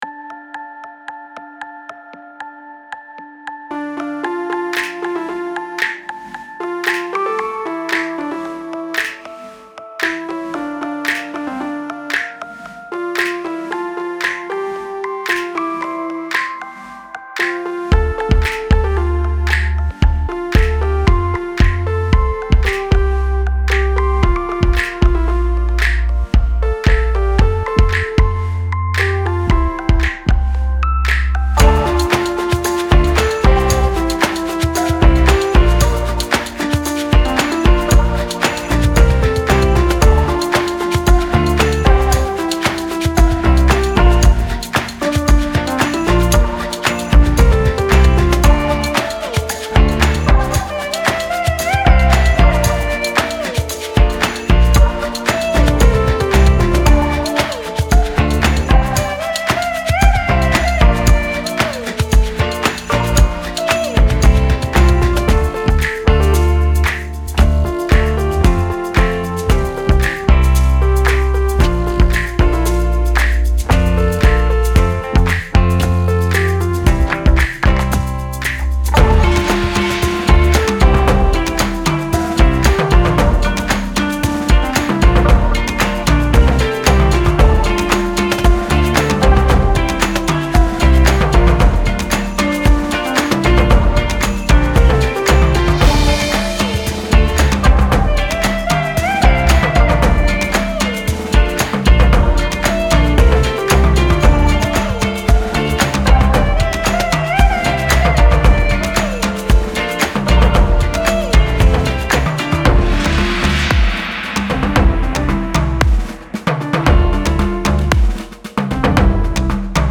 Version instrumentale avec guide-chant